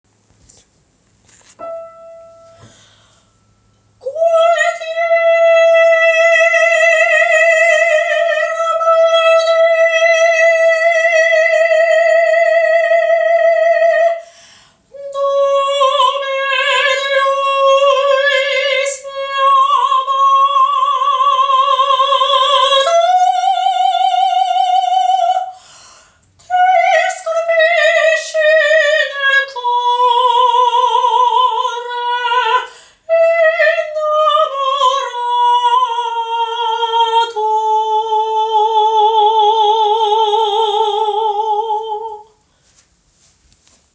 スマホ録音なので
声の奥行きが出ないので
夢見心地なジルダのアリア